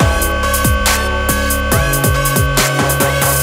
minifun_spin.wav